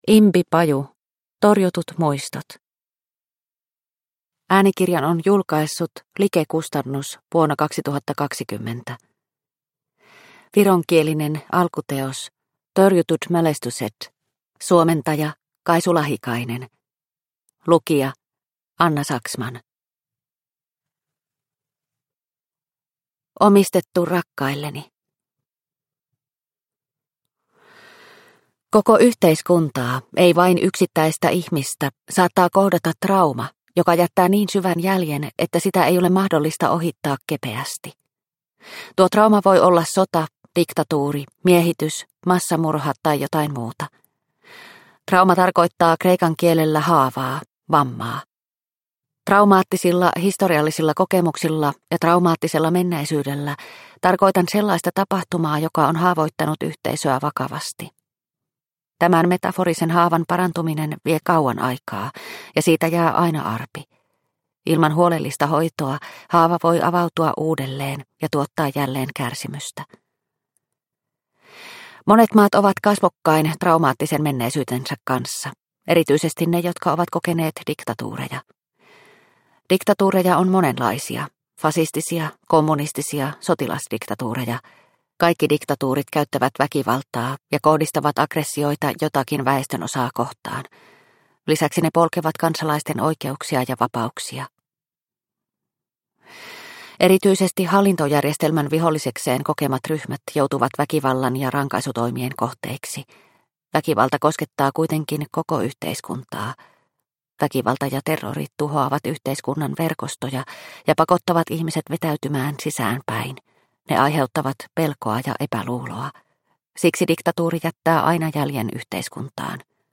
Torjutut muistot – Ljudbok – Laddas ner